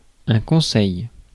Ääntäminen
IPA: /kɔ̃.sɛj/